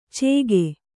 ♪ cēge